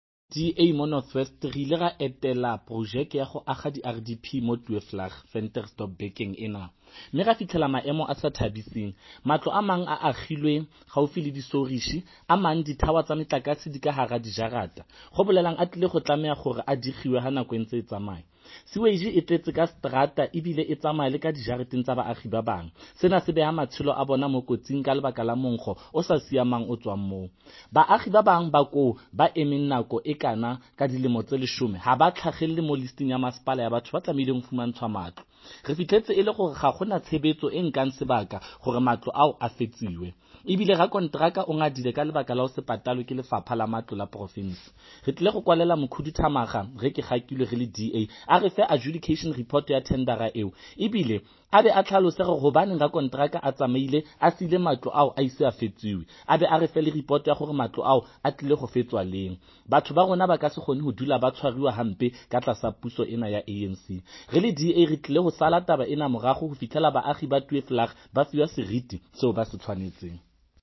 Note to Editors: Kindly find attached soundbites in
Setswana by DA North West Spokesperson on COGHSTA, Freddy Sonakile.